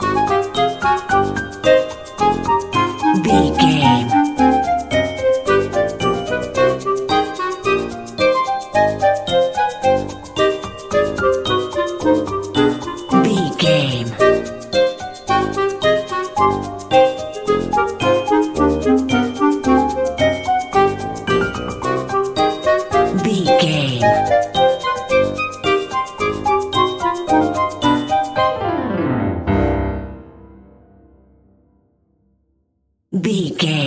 Aeolian/Minor
orchestra
piano
percussion
horns
silly
goofy
comical
cheerful
perky
Light hearted
quirky